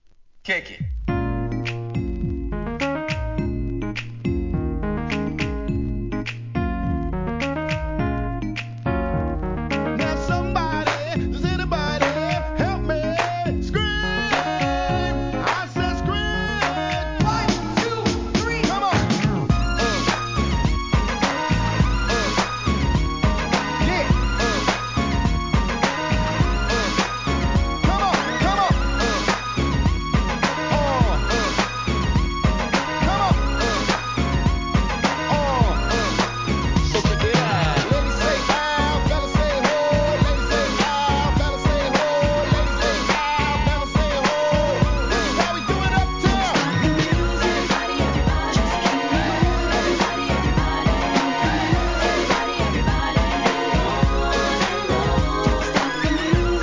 HIP HOP/R&B
人気のPARTY ANTHEM!!